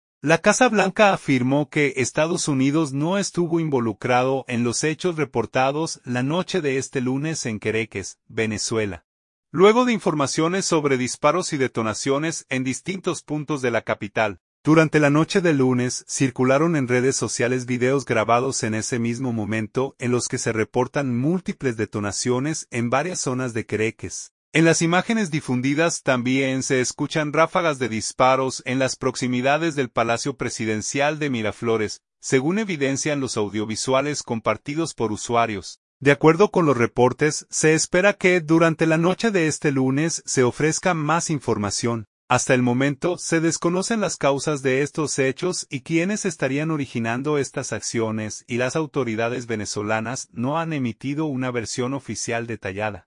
En las imágenes difundidas también se escuchan ráfagas de disparos en las proximidades del Palacio Presidencial de Miraflores, según evidencian los audiovisuales compartidos por usuarios.
Tensión en Caracas: disparos y detonaciones sacuden alrededores del Palacio de Miraflores